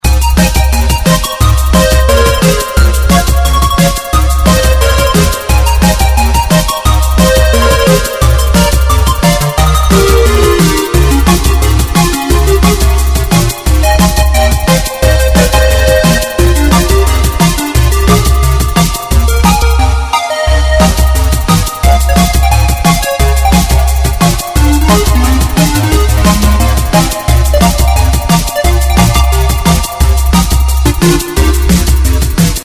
Саундтреки [70]